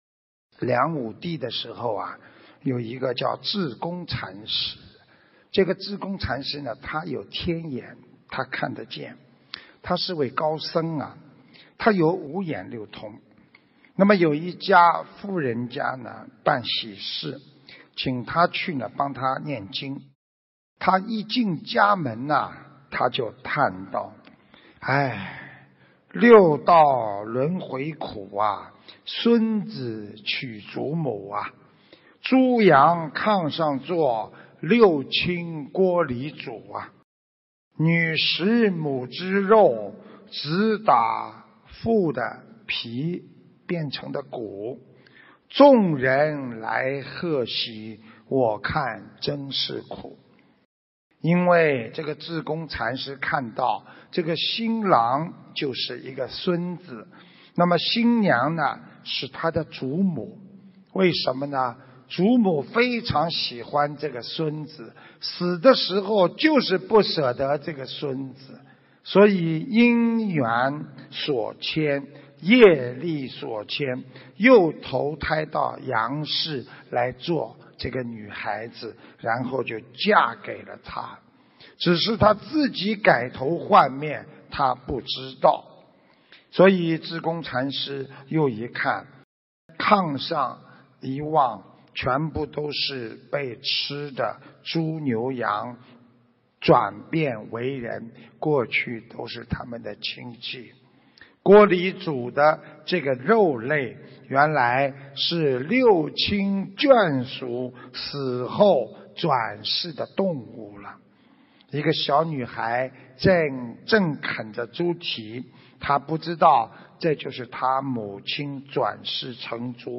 音频：《志公禅师的故事》新加坡观音堂开光开示！